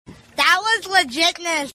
that-was-legitness-meme-sound